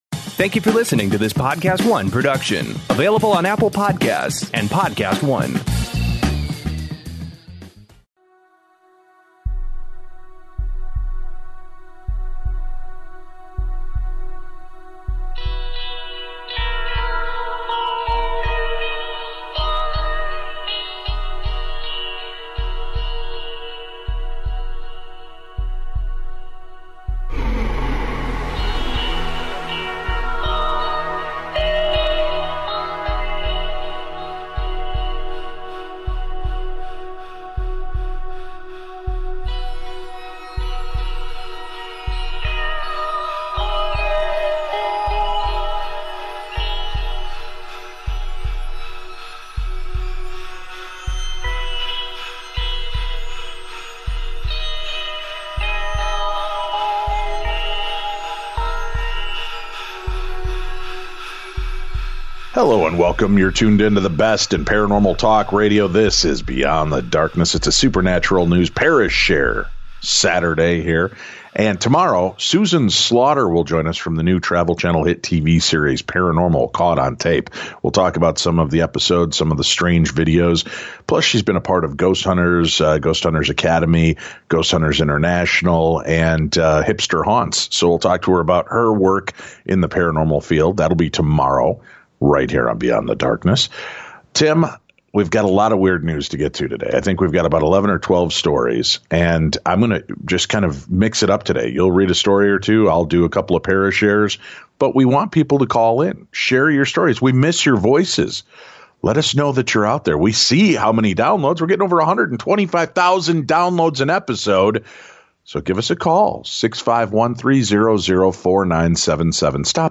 BEYOND the DARKNESS Paranormal Radio Show is back with more Strange Supernatural News & Listeners' Paranormal Encounters!